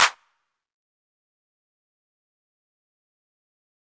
REDD Clap (7).wav